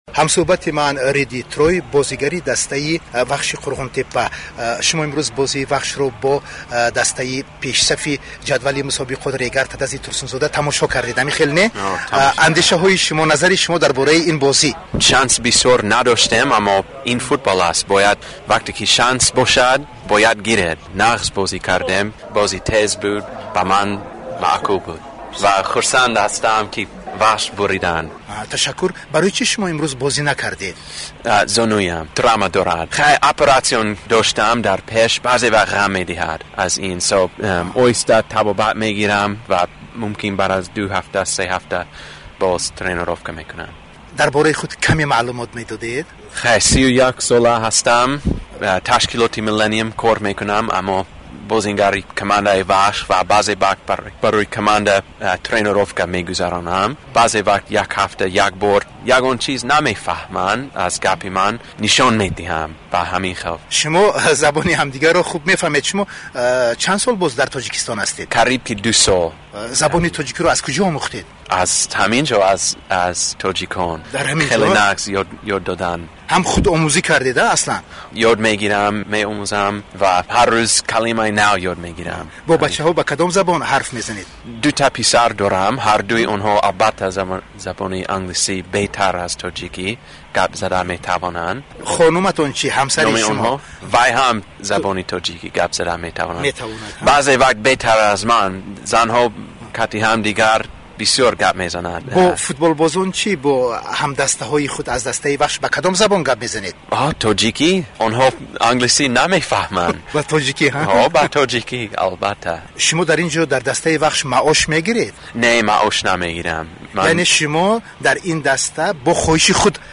Гуфтугӯи